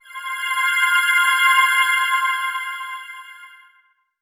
shiny_gems_sparkle_effect_01.wav